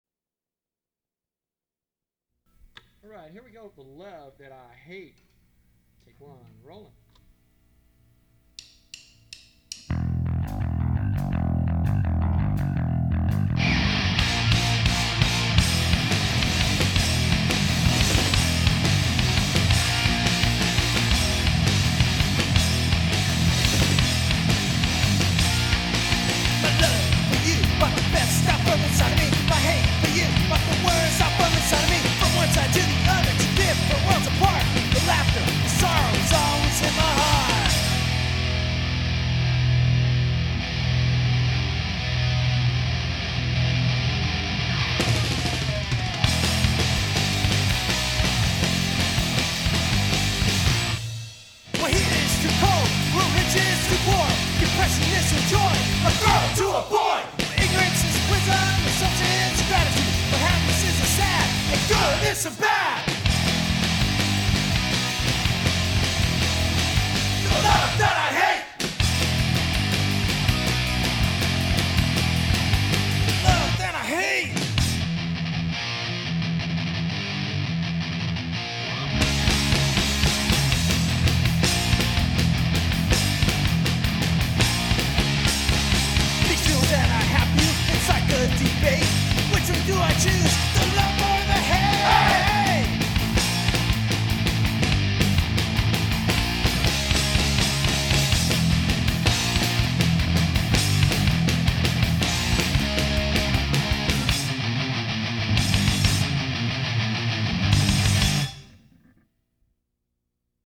These songs are from a 1995 demo session
Vocal
Lead Guitar,Bass
Drums